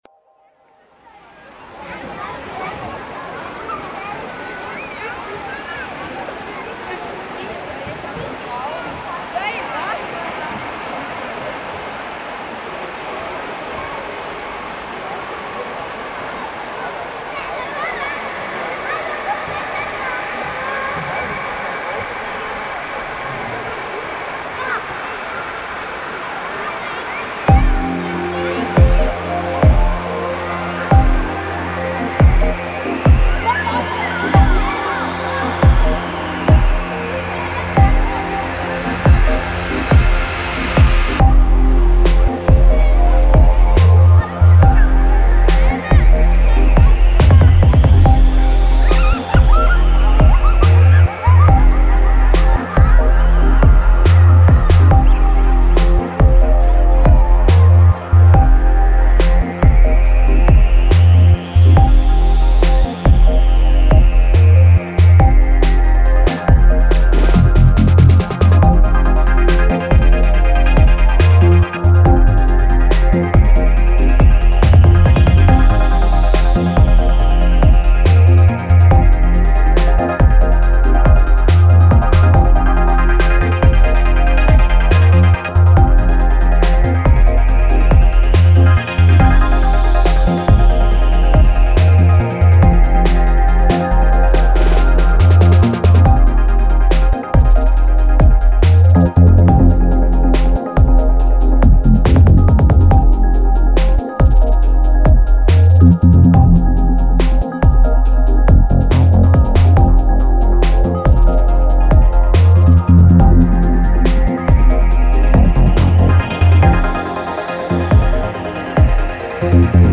Kategorie: Ambient